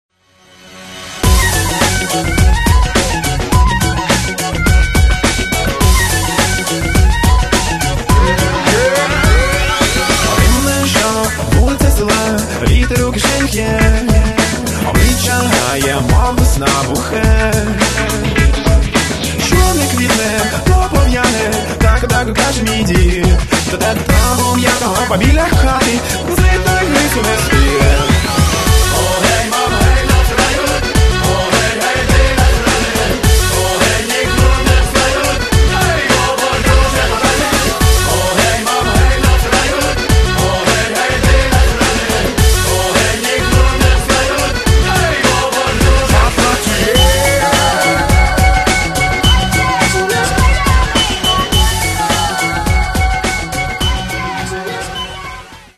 Каталог -> Народная -> Современные обработки